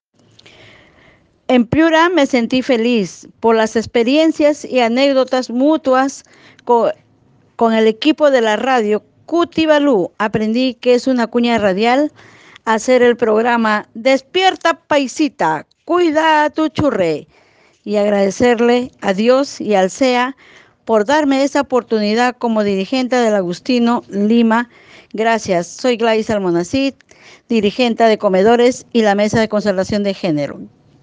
🔴 En vivo / Entrevista con un grupo de lideresas sociales de Lima que forman parte de proyecto de prevención de la violencia Gepostet von Radio Cutivalú am Mittwoch, 25.